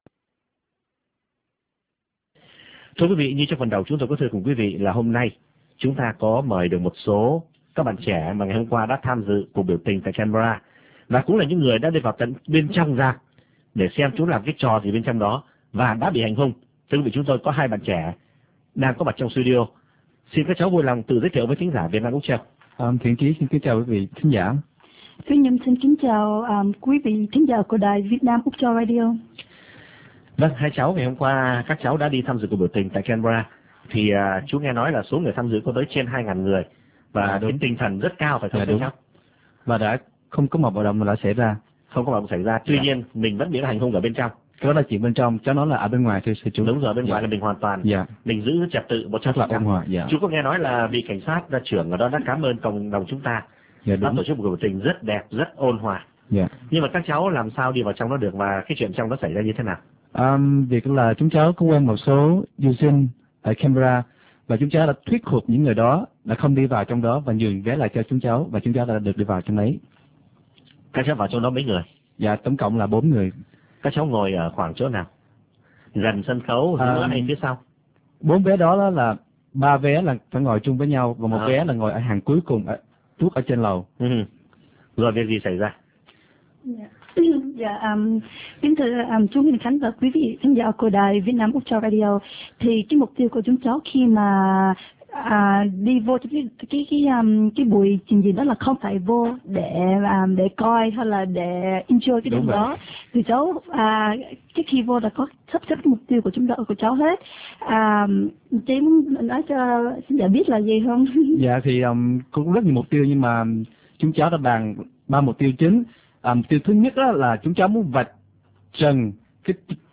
Qu� vị chờ nghe Đ�i PT NV tại �c phỏng vấn 4 thanh ni�n đ� v�o rạp tại Canberra - Nếu chờ l�u vẫn kh�ng nghe được th� h�y bấm v�o đ�y